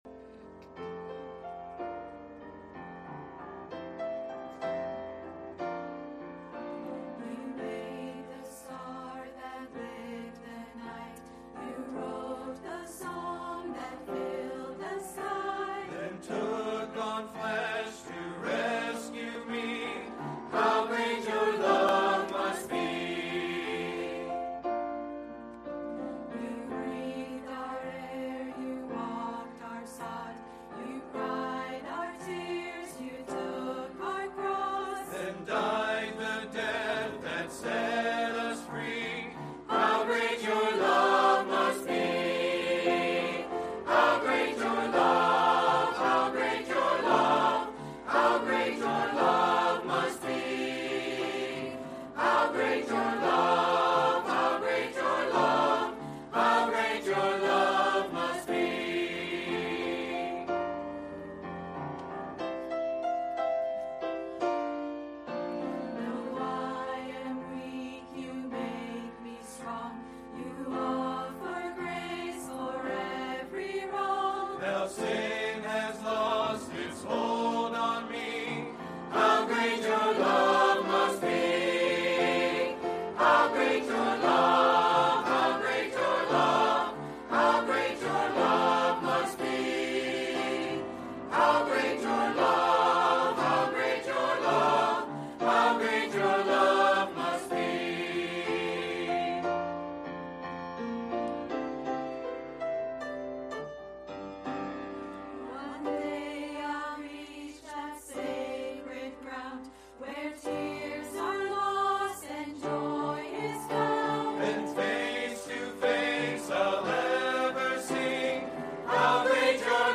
A message from the series "Focused on the Family."